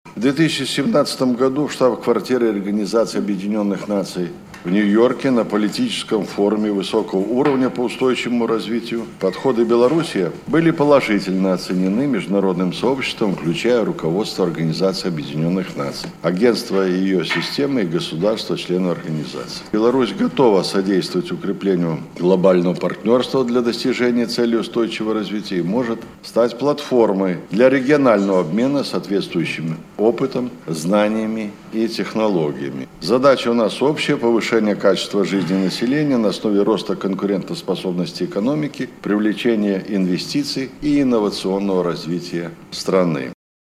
Беларусь готова способствовать укреплению глобального партнерства для достижения Целей устойчивого развития. Это заявил председатель Совета Республики Михаил Мясникович на парламентских слушаниях по этой теме.